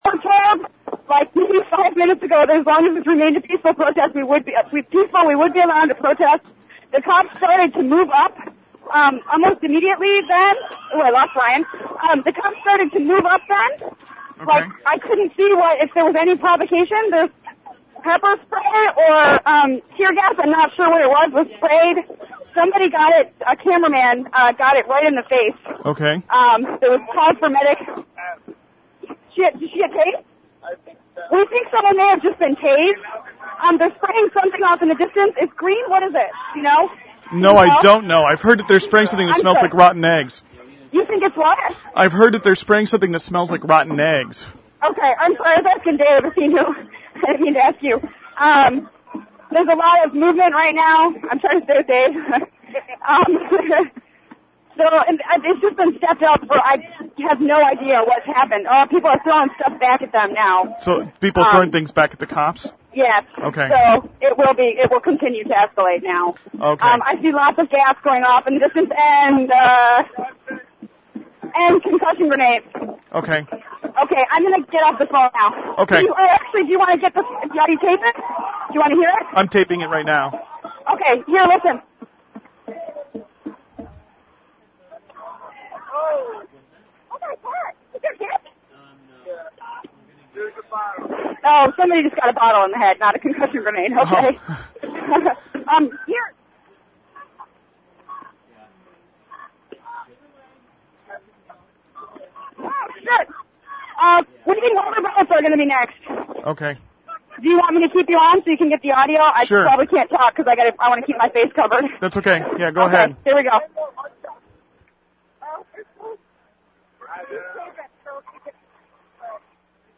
Lastest Audio From The Streets Of Miami: Police Advance Against Peaceful Protestors With Gas And Concussion Grenades